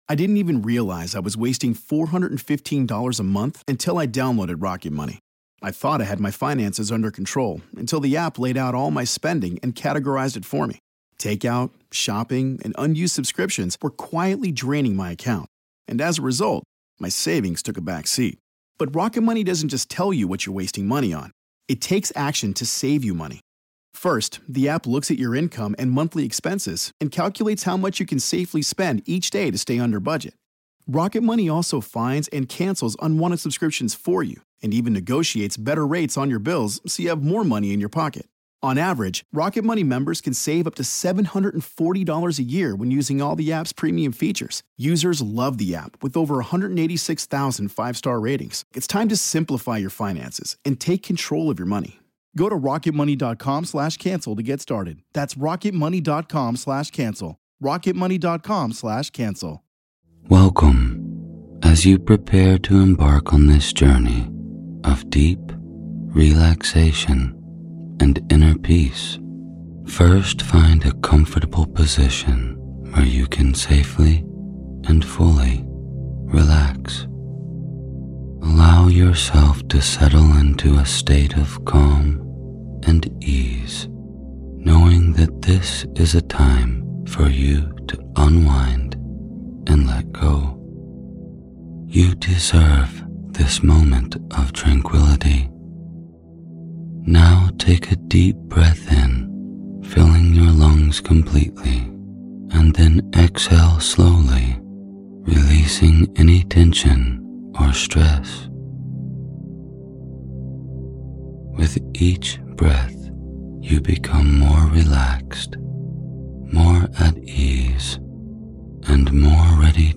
"No More Harmful Thoughts" Guided Sleep Hypnosis
This guided medtation sleep hypnosis session is for anyone struggling with negative harmful thoughts. this could be self harm, low self esteem, or even suicidal ideas. This session will help you relieve these negative emotions.